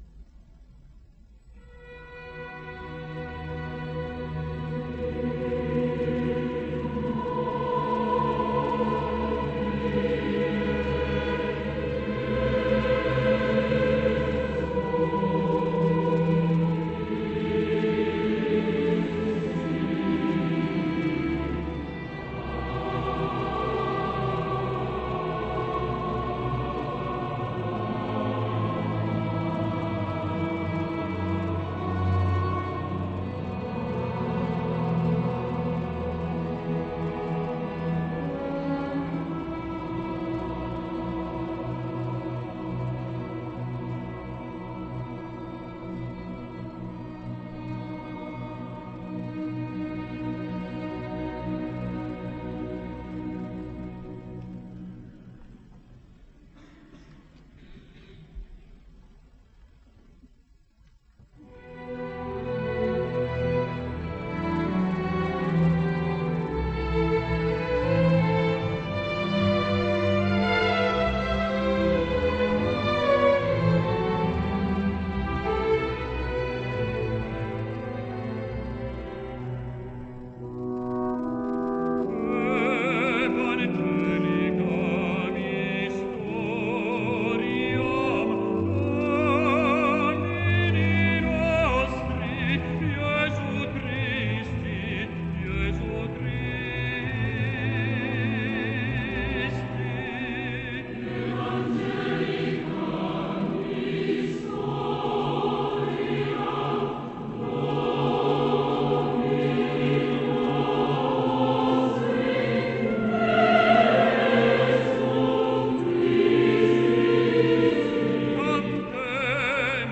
Il Natale del Redentore, Orchestra del Conservatorio di Santa Cecilia
prologo